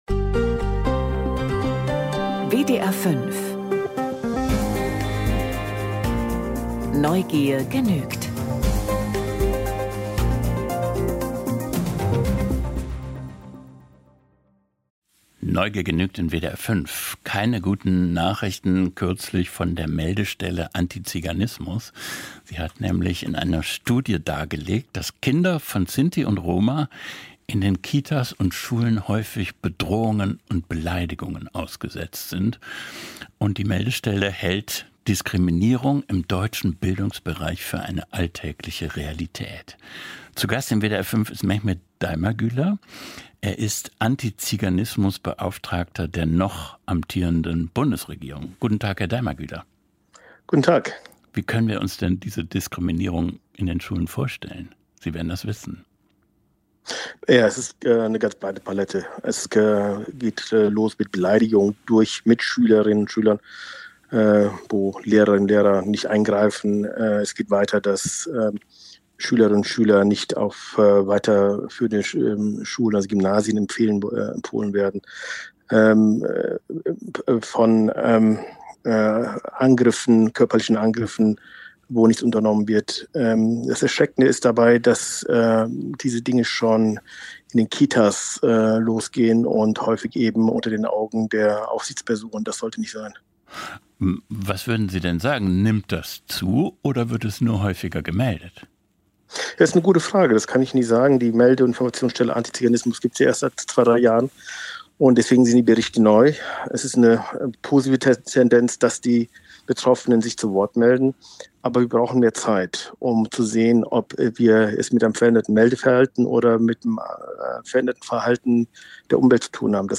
Antiziganismus an deutschen Schulen - Antiziganismusbeauftragter Dr. Daimagüler im WDR - Melde- und Informationsstelle Antiziganismus